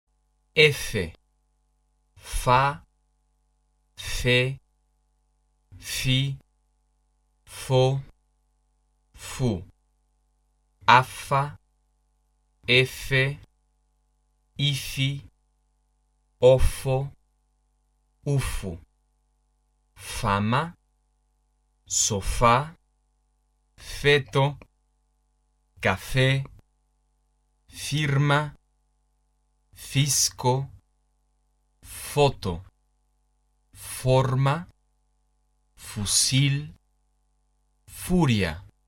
名称是efe，音标是【f】。
f】是唇齿擦清辅音，发音时，上门齿和下唇轻轻接触，上齿露出，唇角向两侧咧开。气流从唇齿之间的缝隙通过。声带不振动。这个音和汉语拼音里面的f发音是一样的。